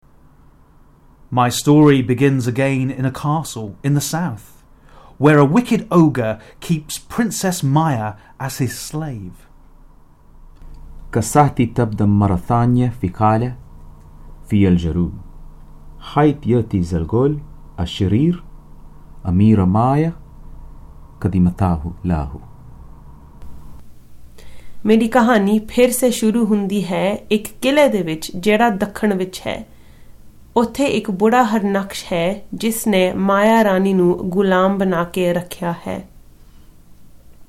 These are phrases that emerge from the story-telling box in the programme...